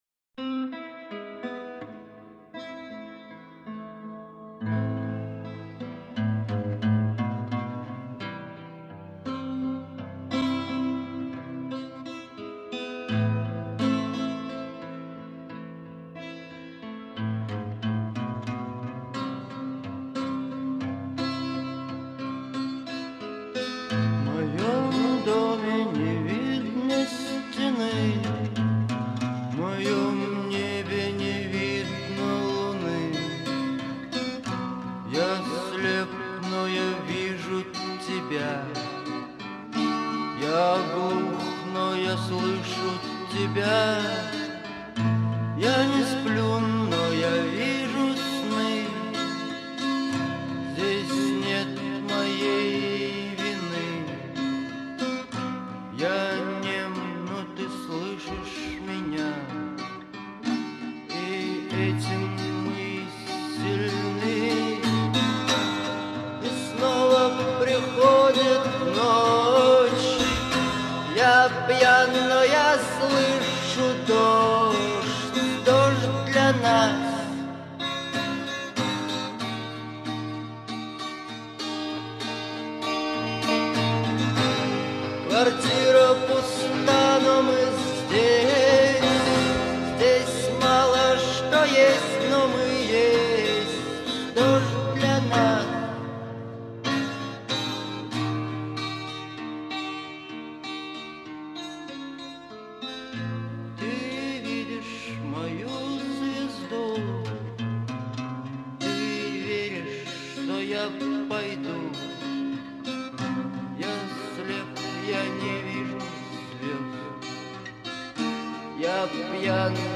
Пост панк